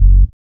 4508R BASS.wav